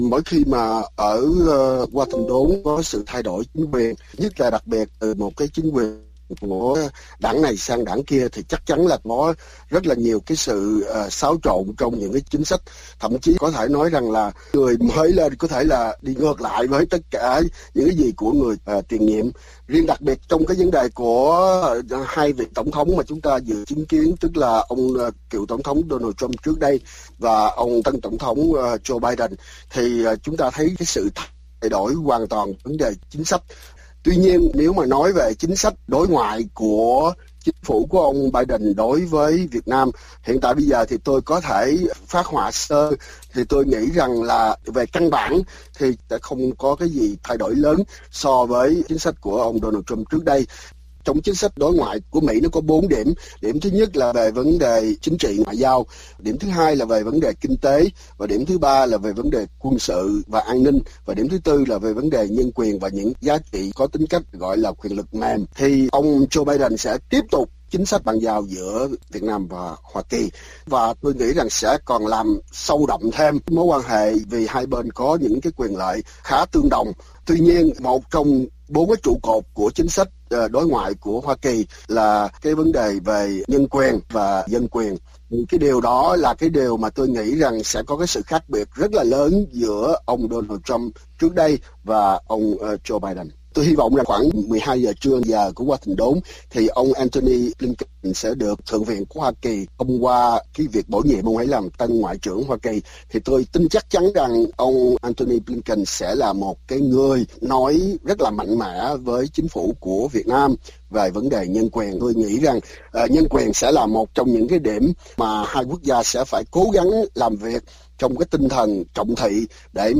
Đài RFA thực hiện cuộc phỏng vấn ngắn